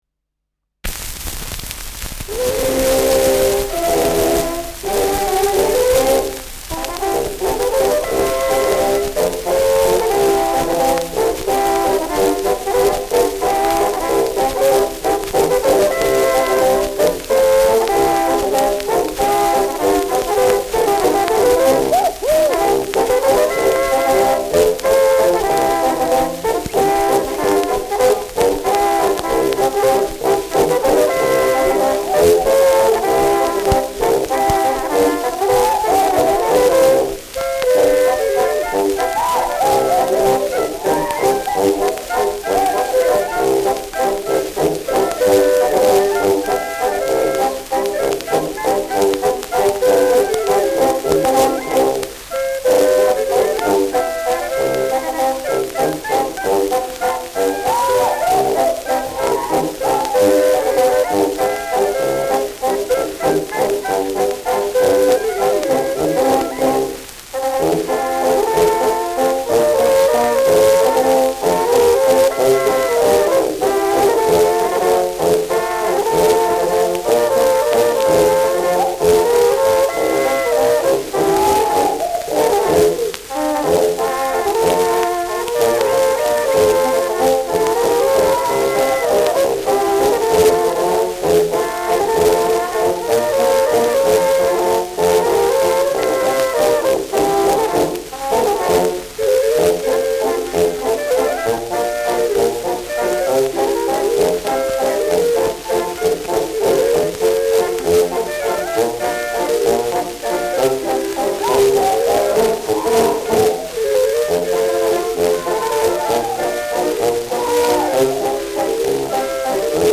Schellackplatte
starkes Rauschen : leiert : abgespielt : gelegentliches Knacken
Dachauer Bauernkapelle (Interpretation)
Bauernkapelle Salzburger Alpinia (Interpretation)